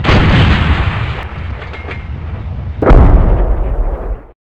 canon_g.ogg